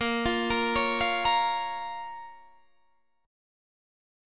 A#m chord